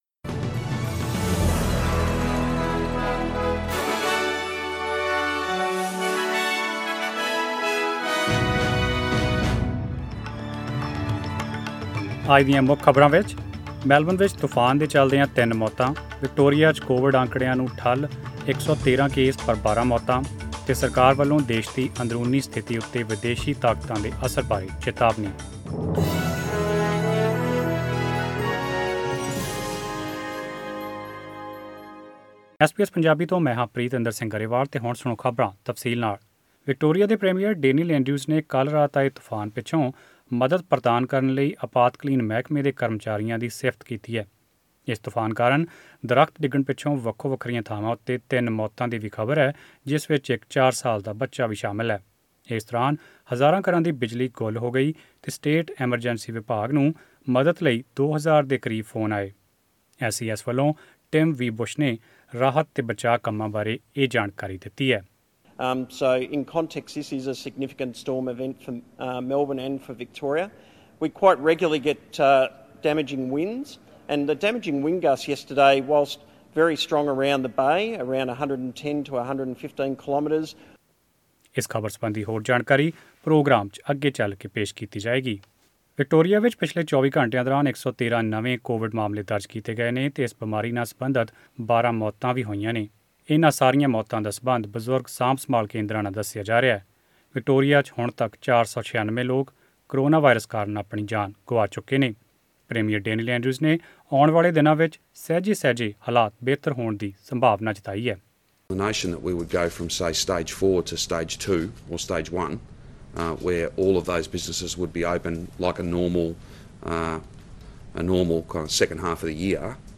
Australian News in Punjabi: 28 August 2020